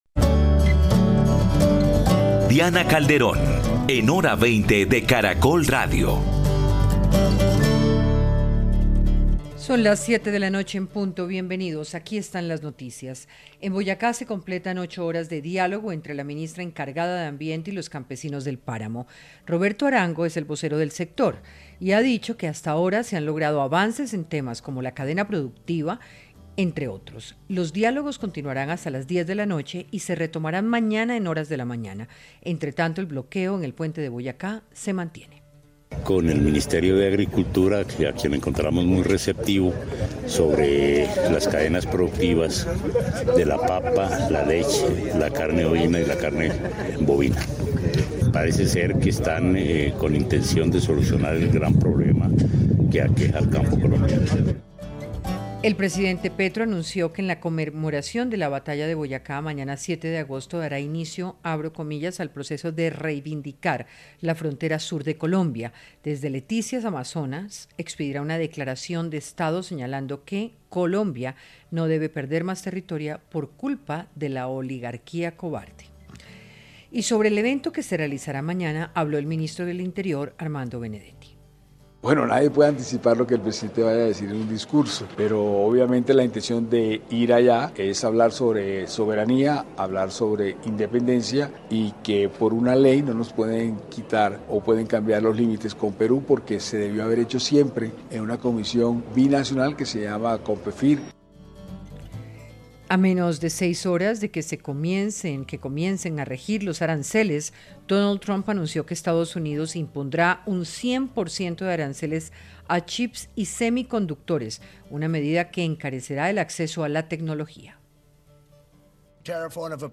Panelistas analizaron el panorama que tiene el país faltando un año para terminar el Gobierno Petro. Debatieron sobre los aciertos, las equivocaciones y lo que le reconocen desde la oposición.